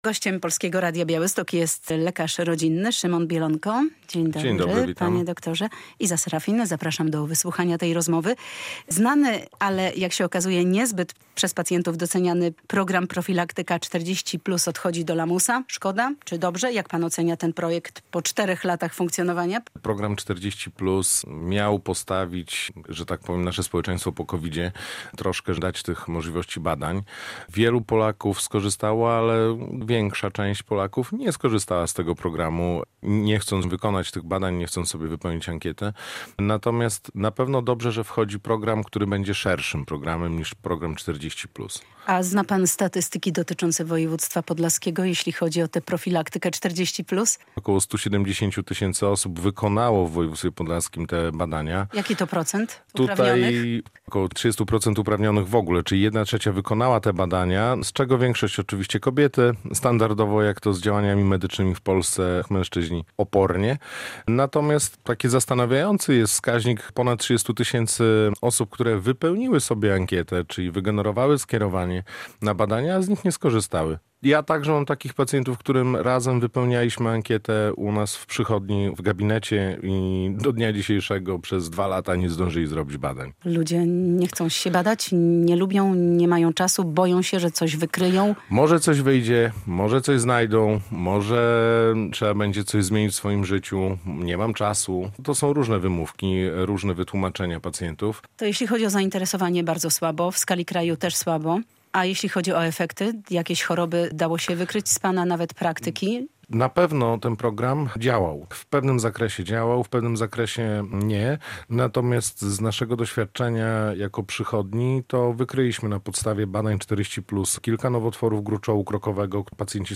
lekarz rodzinny